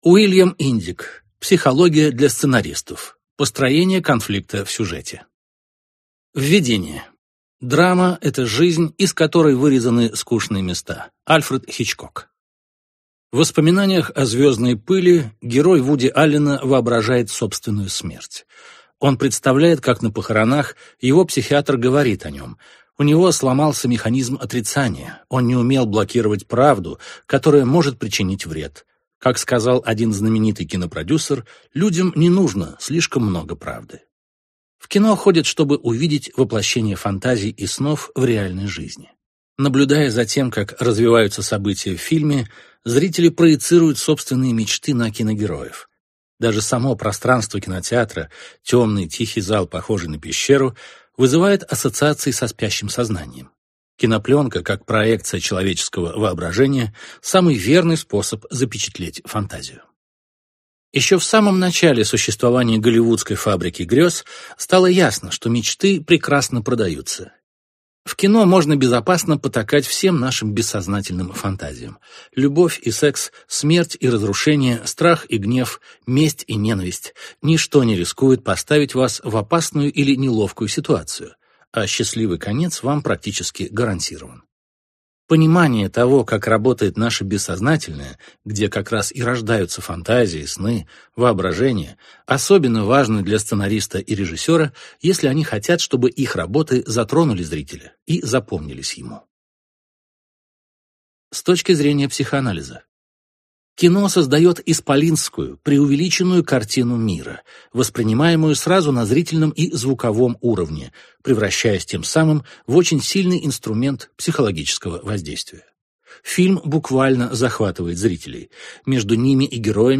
Аудиокнига Психология для сценаристов. Построение конфликта в сюжете | Библиотека аудиокниг